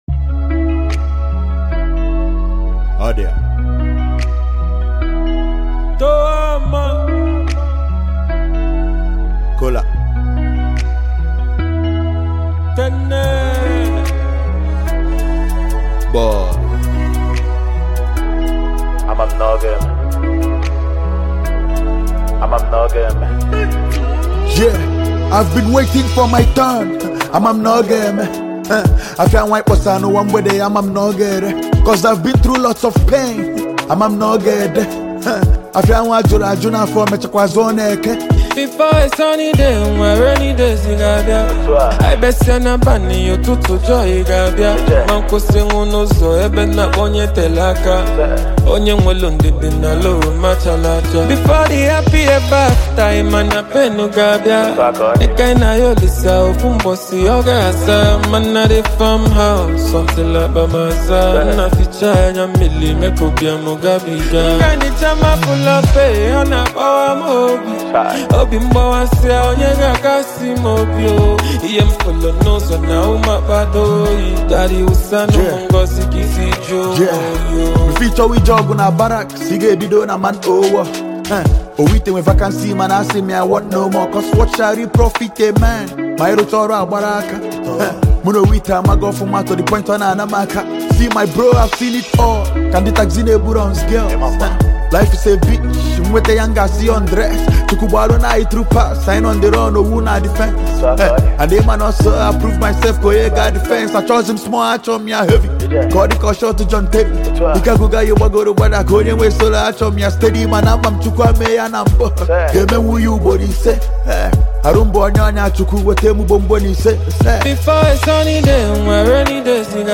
a blend of witty lyrics, street rhythm, and cultural spice